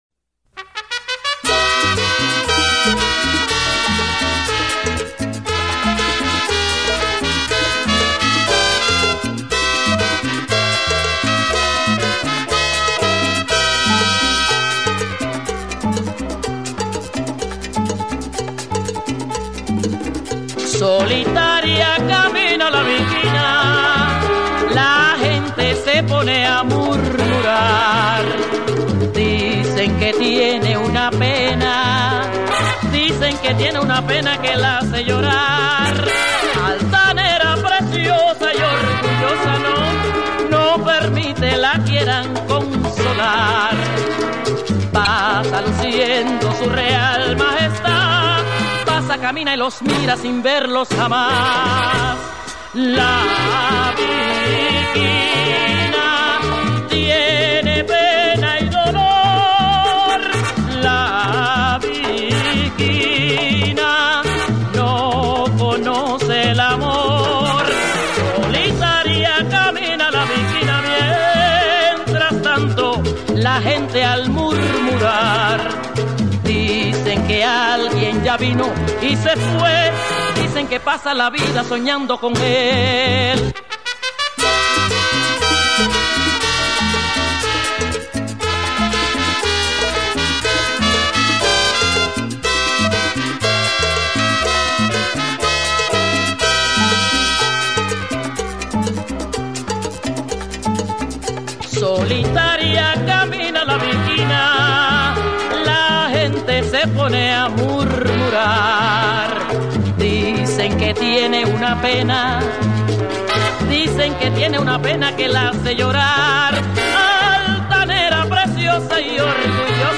1964   Genre: Latin   Artist